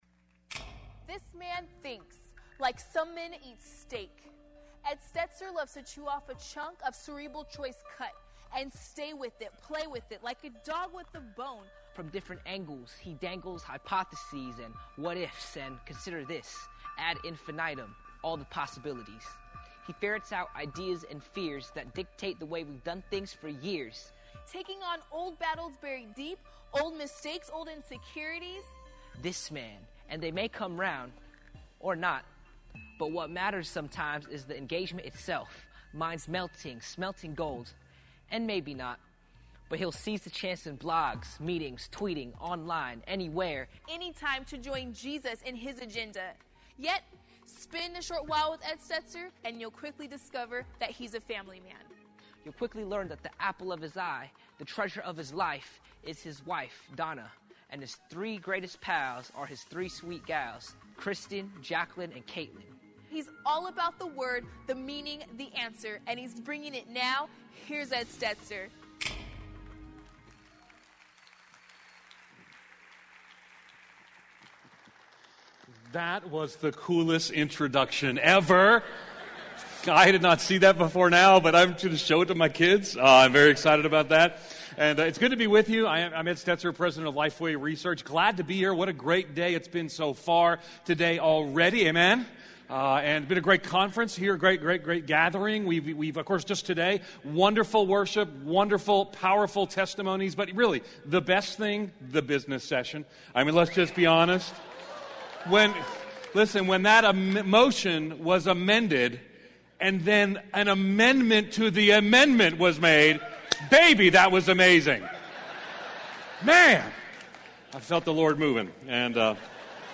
Recorded live at Connection 2012 in Phoenix.